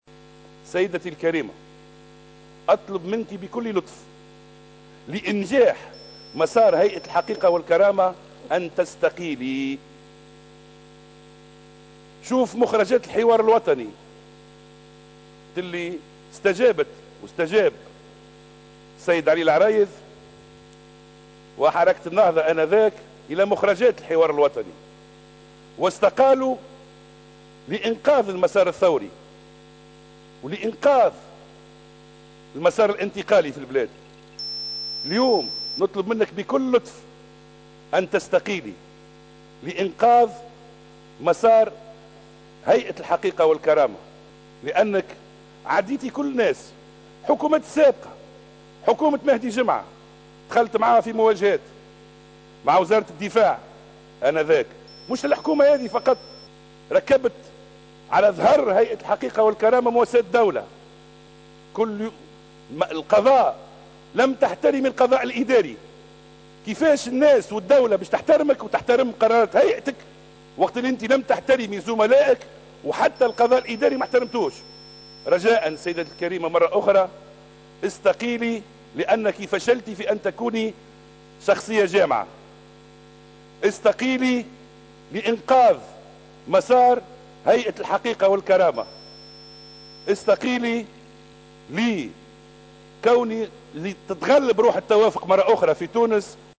استقيلي" دعا النائب بمجلس نواب الشعب وليد جلاّد رئيسة هيئة الحقيقة و الكرامة سهام بن سدرين لتقديم استقالتها لإنجاح مسار الهيئة. وفي مداخلة له اليوم الأربعاء خلال جلسة عامة مخصّصة لمناقشة ميزانية الهيئة و المصادقة عليه، ذكّر جلاّد سهام بن سدرين باستجابة حركة النهضة لمخرجات الحوار الوطني و تقديم رئيس الحكومة الأسبق علي العريض لاستقالته و المساهمة بذلك في إنقاذ المساريْن الثوْري و الانتقالي في البلاد.